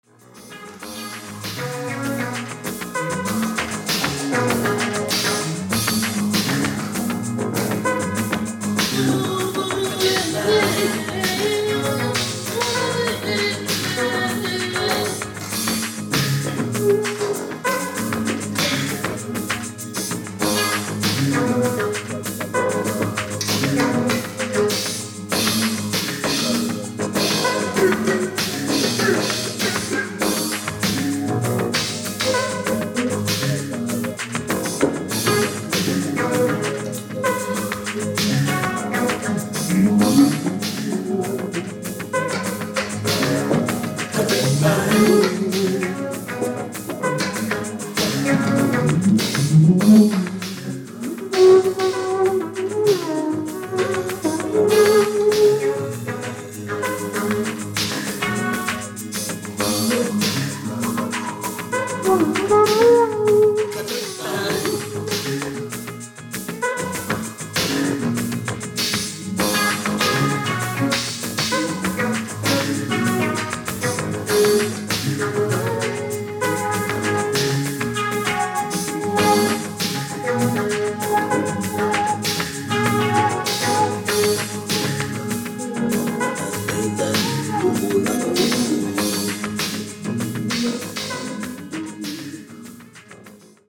シンセサイザーを使ったJAZZ FUSION!!!